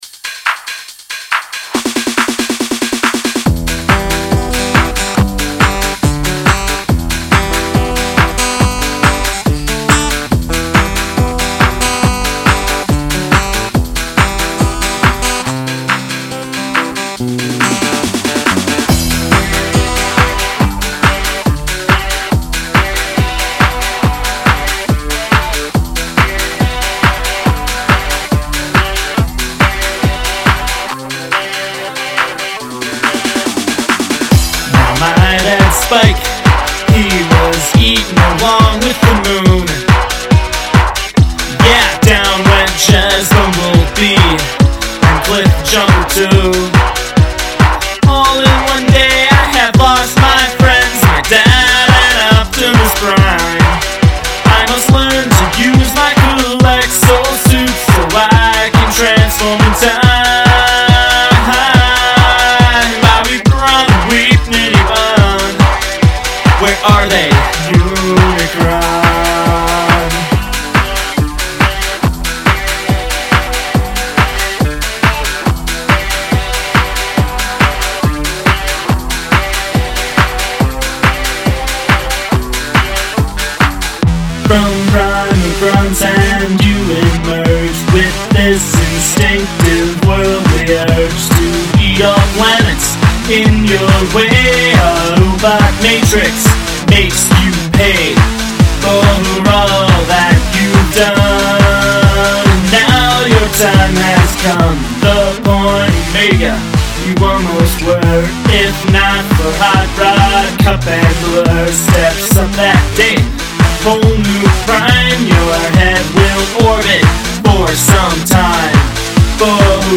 -A Taylor 412 CE
-Epiphone Les Paul Standard electric
-Eurorack MX 802A Mixer
-Alesis NanoCompressor
-Boss GT-3 Effects processor (on mic and guitars)
-MXL V57M (recording mic)